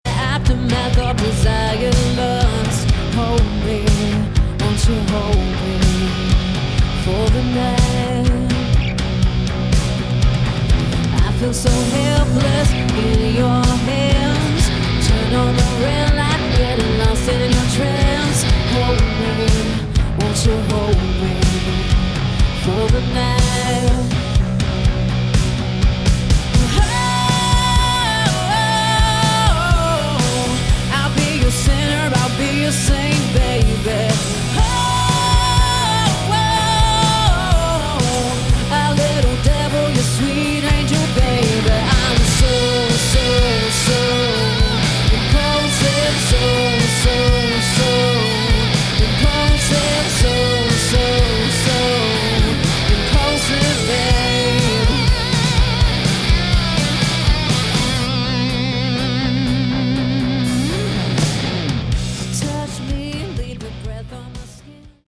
Category: Hard Rock
guitar, vocals
bass
keyboards
drums